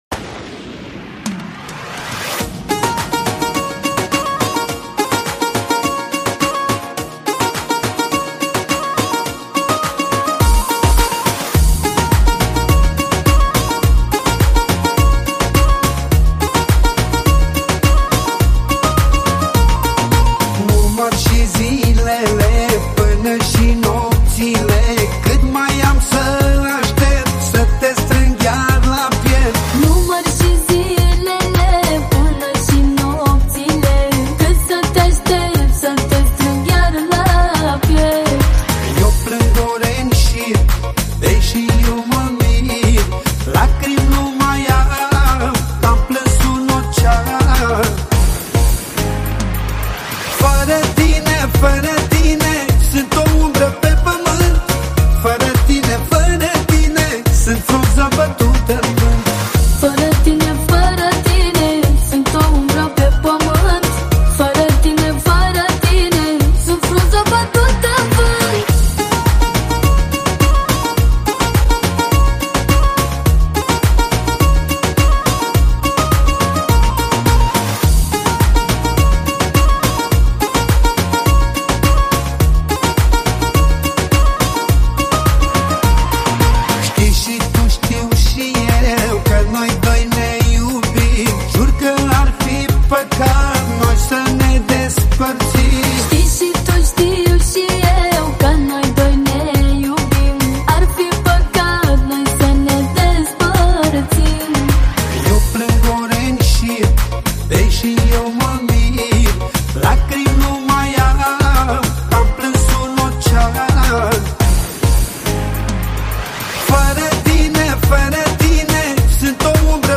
o piesă care îmbină emoție și ritm
Manele Vechi